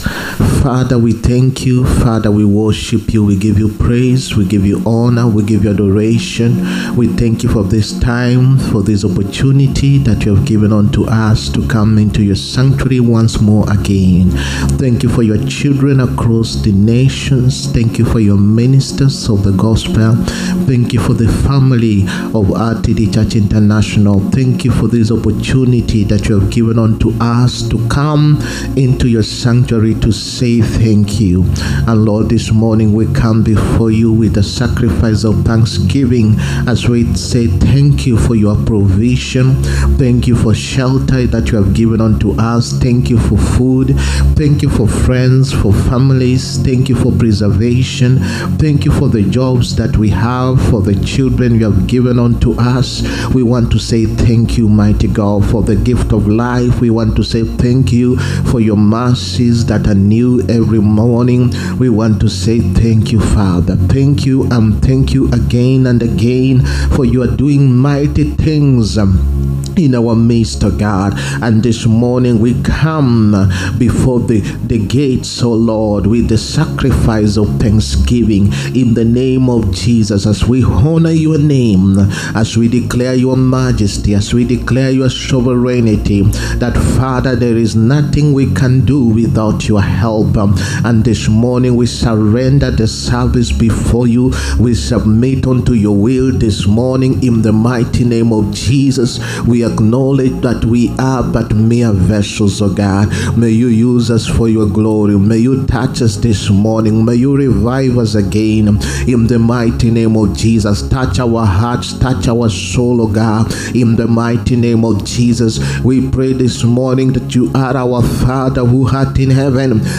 SUNDAY WORSHIP SERVICE. THE GRACE OF GIVING. 16TH MARCH 2025. PART 1.